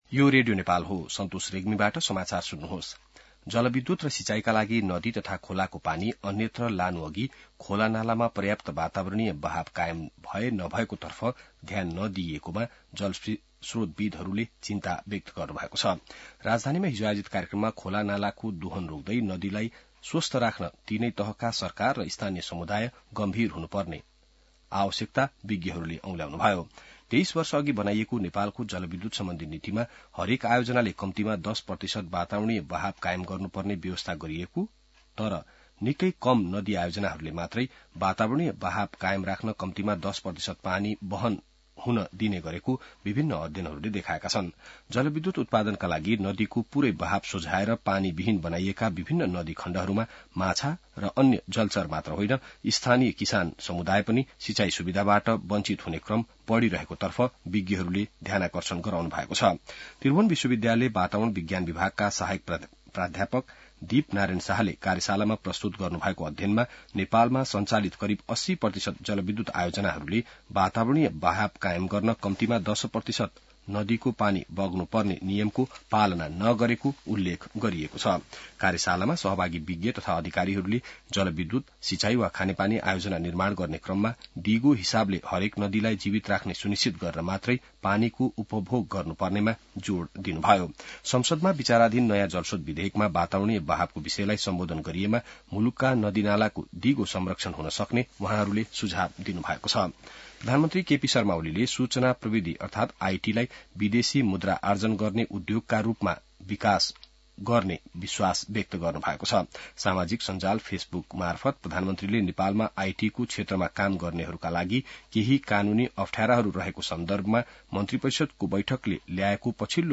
बिहान ६ बजेको नेपाली समाचार : २९ पुष , २०८१